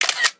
ShutterClick.wav